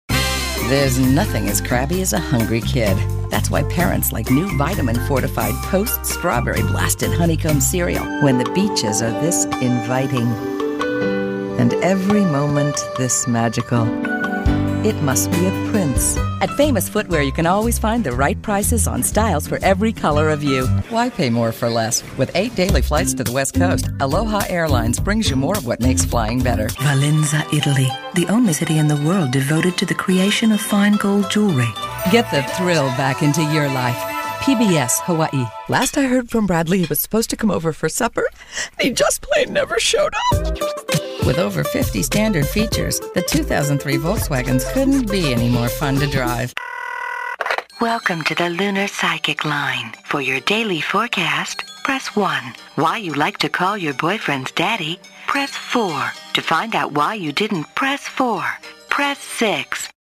Female Voice Talent